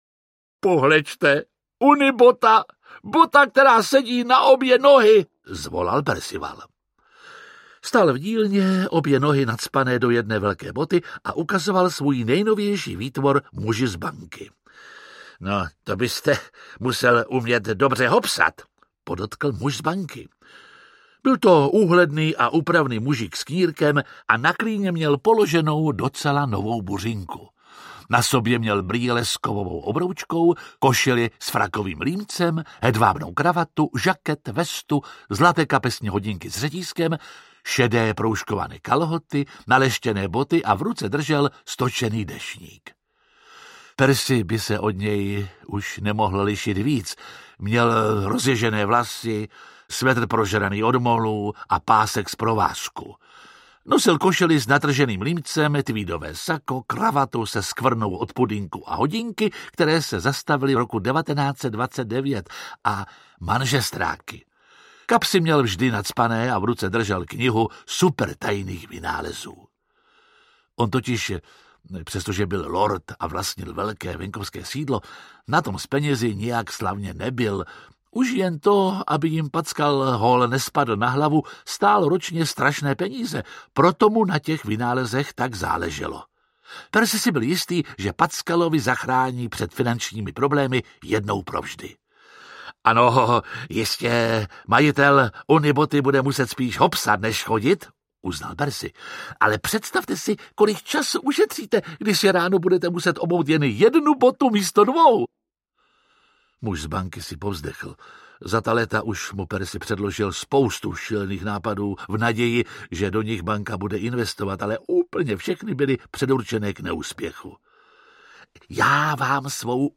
Audiobook
Read: Jiří Lábus